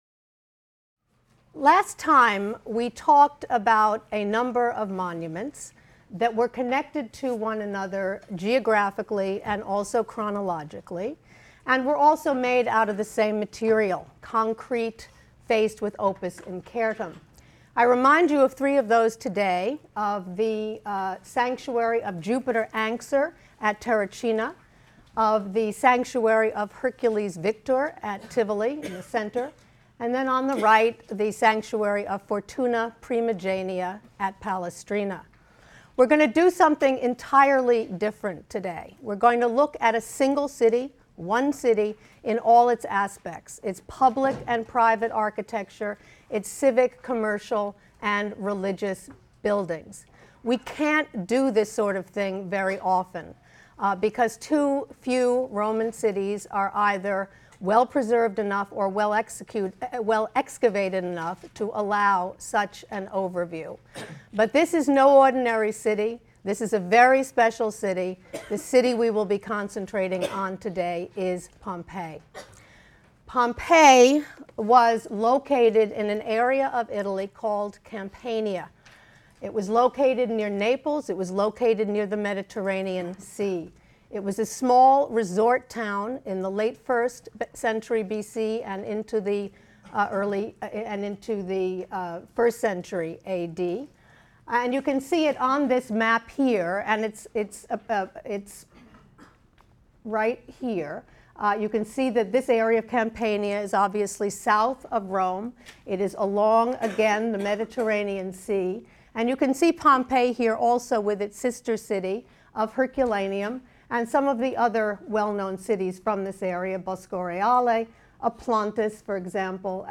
HSAR 252 - Lecture 4 - Civic Life Interrupted: Nightmare and Destiny on August 24, A.D. 79 | Open Yale Courses